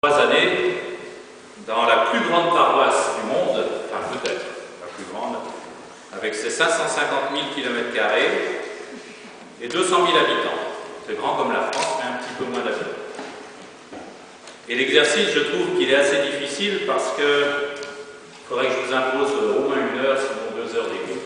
le dimanche 1er Août à Poilley
1 - Messe concélébrée
Homélie - Témoignage